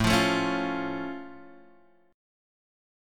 A+ chord